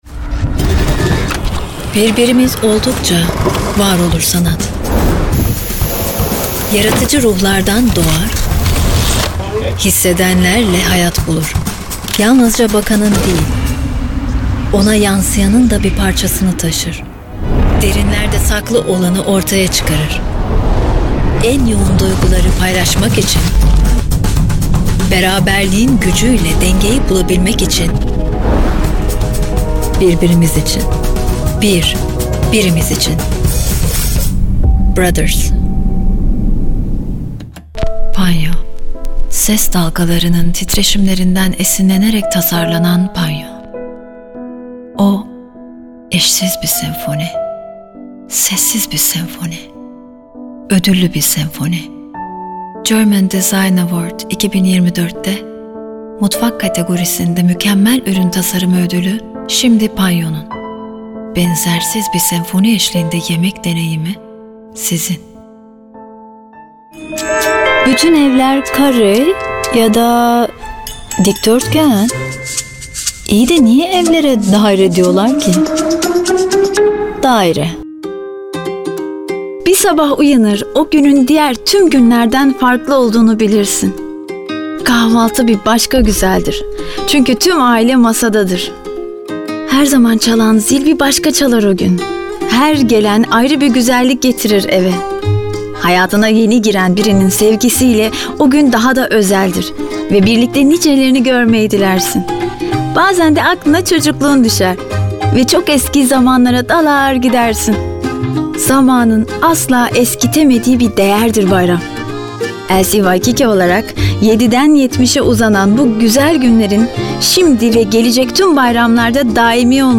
Çeşitli reklamlarda seslendirme yapmıştır.